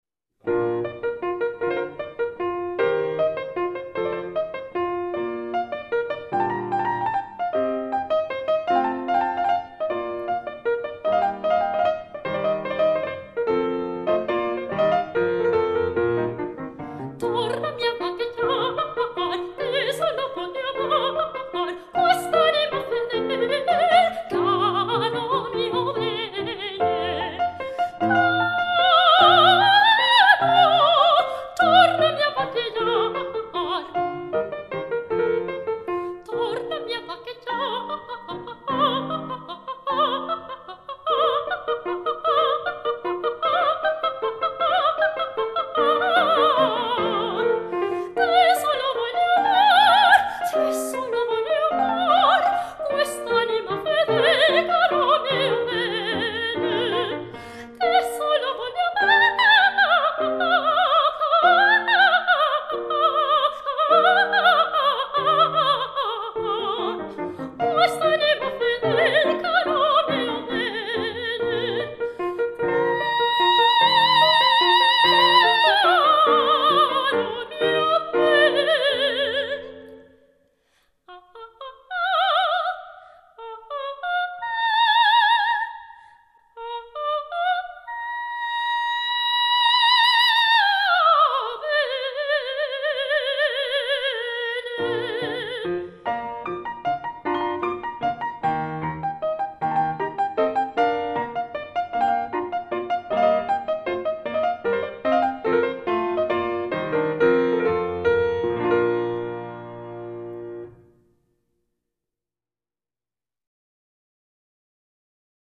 Soprano
Lieder und Arien